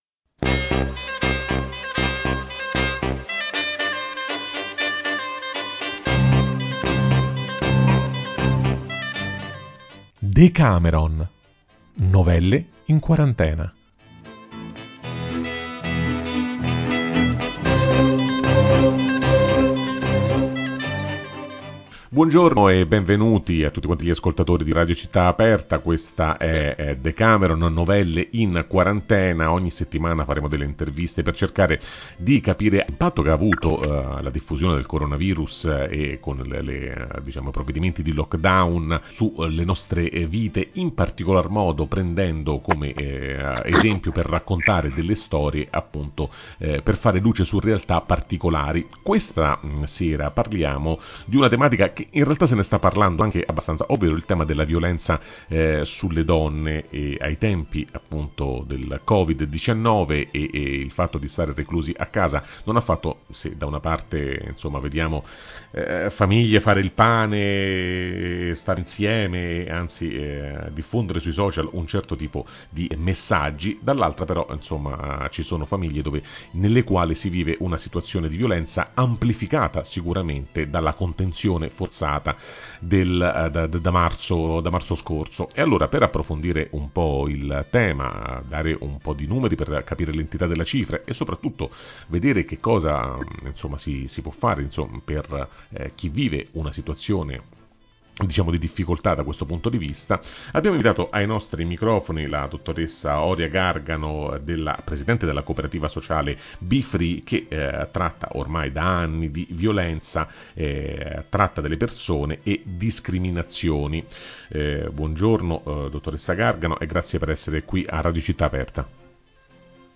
“Non pervenute”: come la quarantena ha fatto sparire le donne dal dibattito pubblico [Intervista